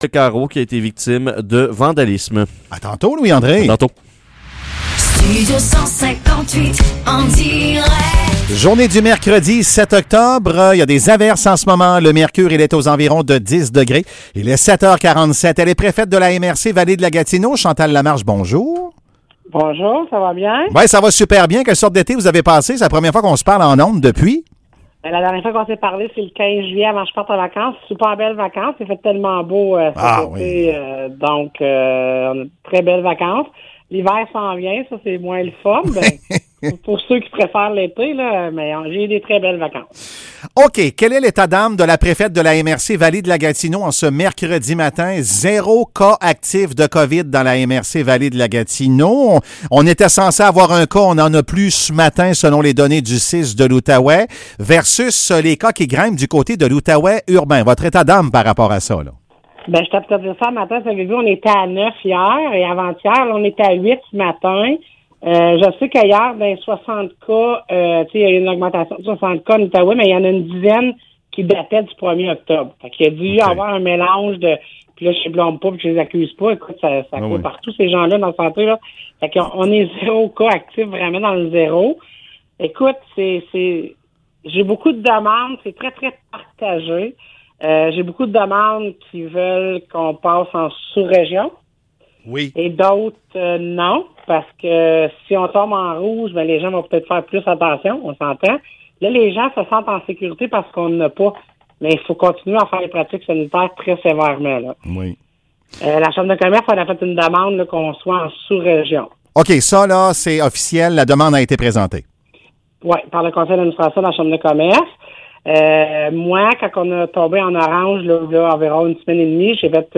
Entrevue avec Chantal Lamarche, préfète de la MRC Vallée-de-la-Gatineau | CHGA
Entrevues
entrevue-avec-chantal-lamarche-prefete-de-la-mrc-vallee-de-la-gatineau.mp3